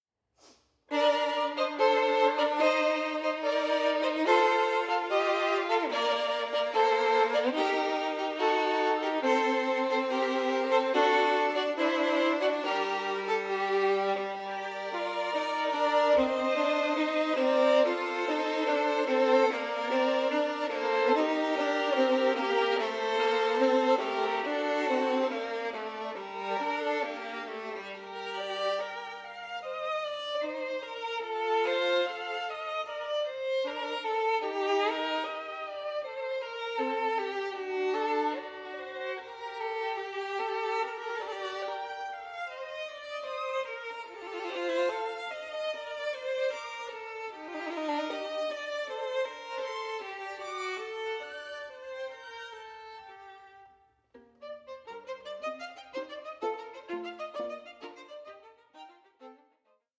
for 2 Violins